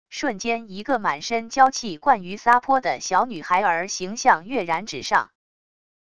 瞬间一个满身娇气惯于撒泼的小女孩儿形象跃然纸上wav音频生成系统WAV Audio Player